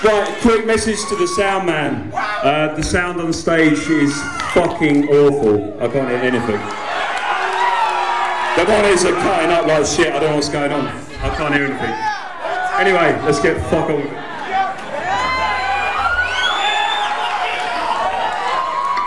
location Phoenix, Arizona, USA venue Old Brickhouse Grill